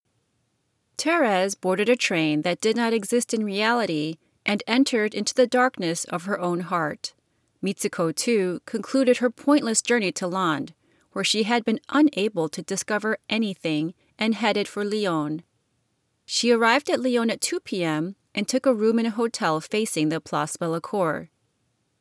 A quiet, high-pitch “tee tee tee tee?” It took me a while to find it. On first pass it just sounded like you reading a story. It’s been described as “beeping.”